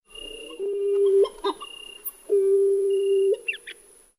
ʻUaʻu (Hawaiian Petrel) Call
uau-hawaiian-petrel-call.wav